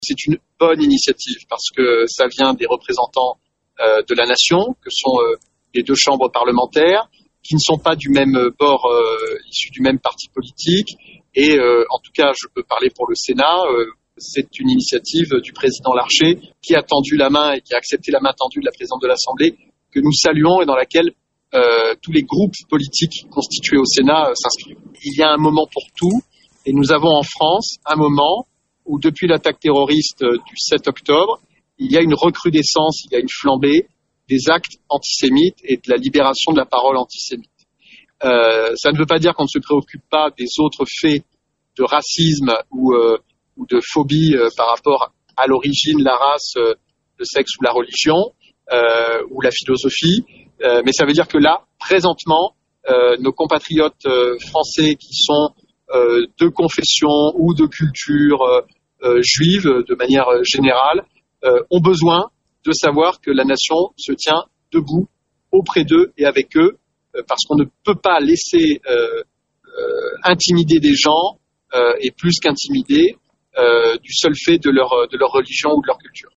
Parmi les parlementaires du département appelant à cette marche, le sénateur Mickaël Vallet.